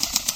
PixelPerfectionCE/assets/minecraft/sounds/mob/bat/loop.ogg at ca8d4aeecf25d6a4cc299228cb4a1ef6ff41196e